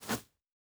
Jump Step Grass A.wav